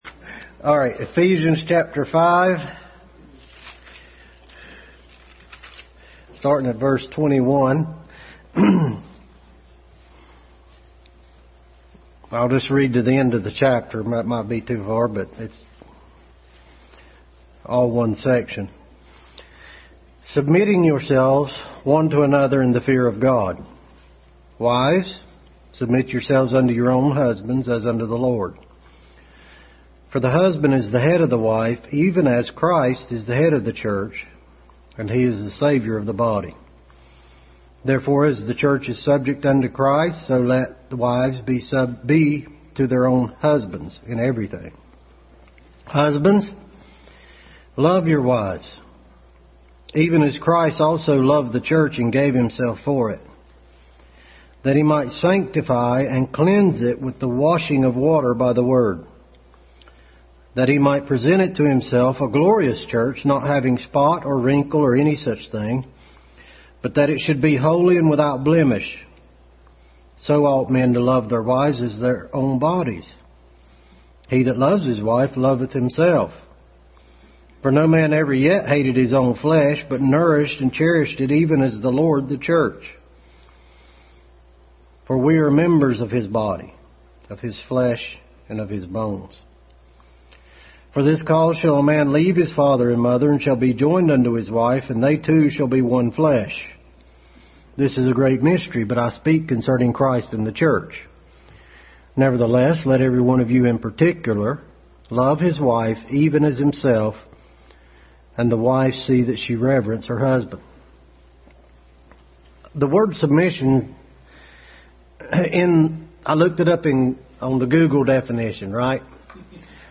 Adult Sunday School continued study in Ephesians.
Ephesians 5:21-33 Service Type: Sunday School Adult Sunday School continued study in Ephesians.